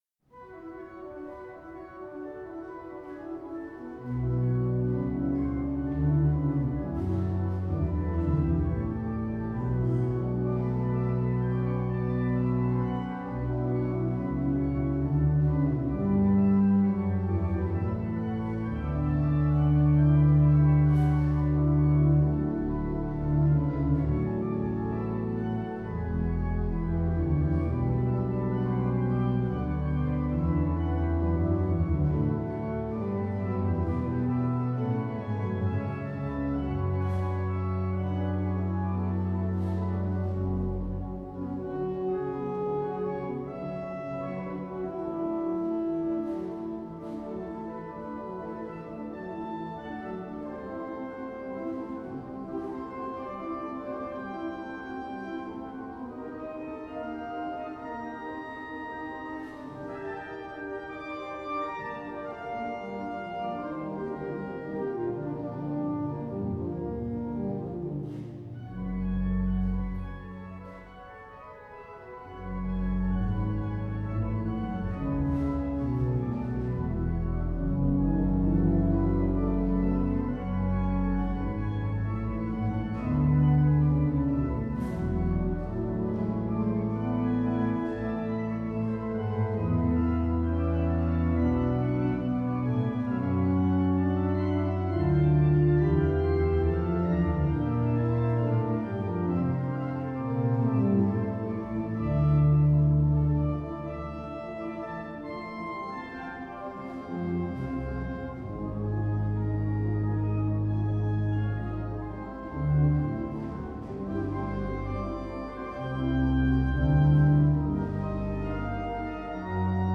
in the Priory Church through the centuries